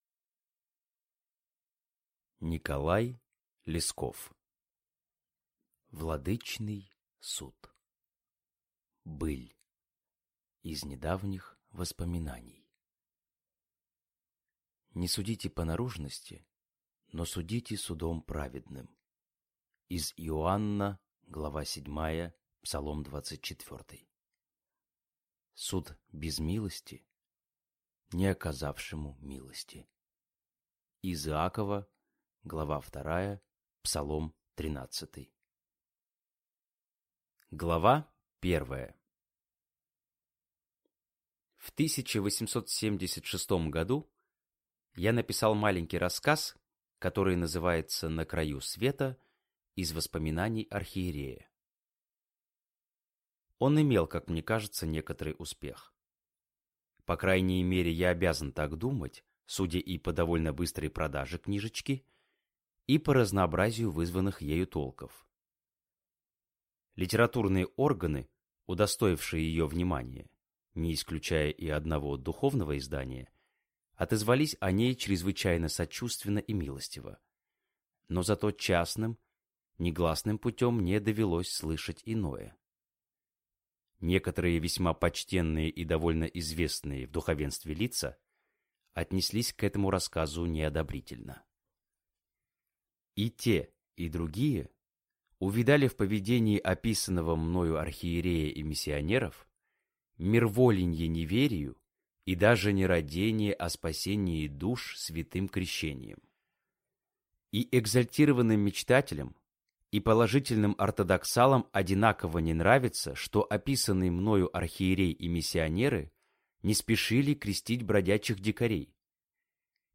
Аудиокнига Владычный суд | Библиотека аудиокниг